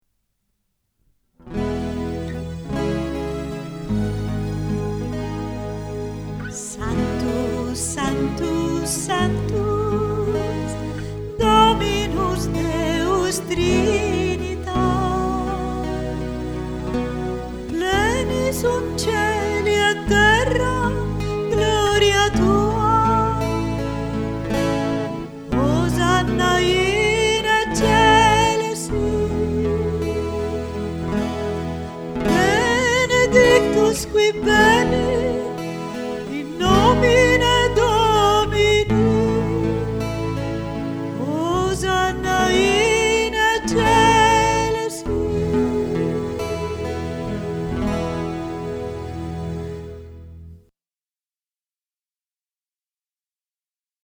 8) Preghiera mp3 – Canto nr. 156 Sanctus Trinitas mp3